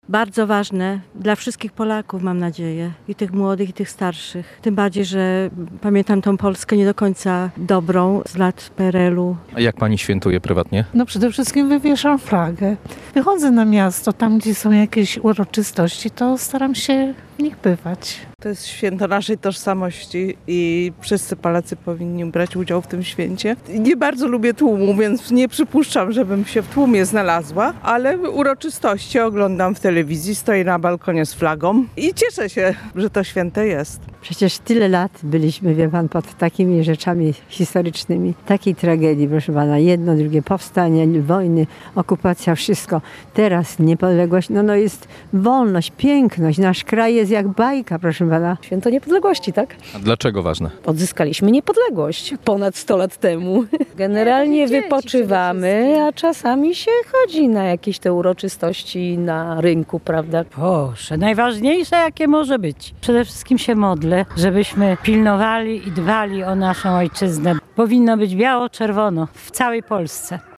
Sonda uliczna w Rzeszowie – Święto Niepodległości
sonda-niepodleglosc.mp3